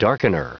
Prononciation du mot darkener en anglais (fichier audio)
Prononciation du mot : darkener